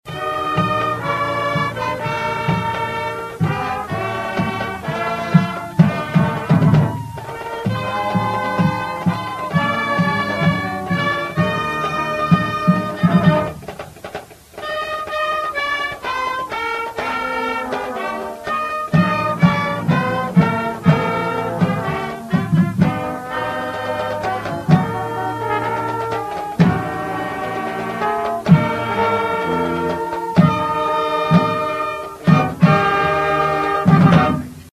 Forty-two floats lit up downtown Cadiz to kick off the holiday season during the Cadiz-Trigg County Christmas Parade Saturday night.